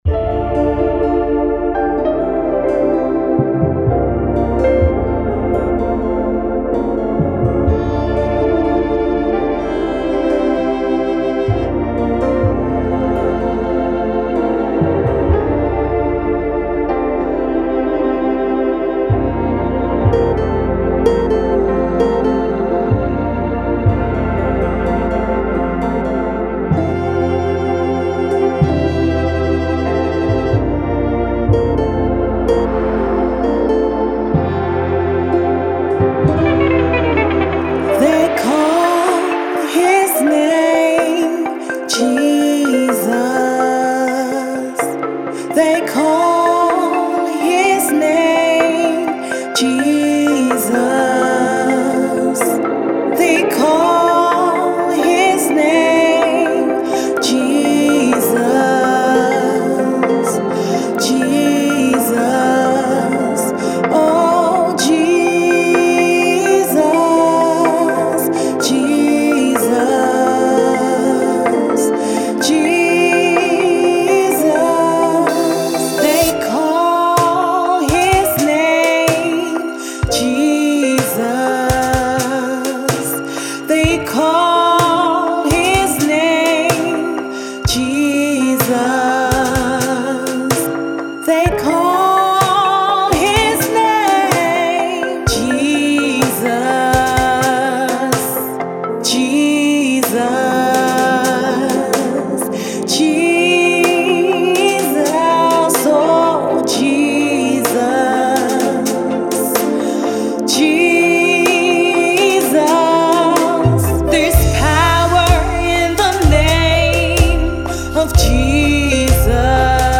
New York based music group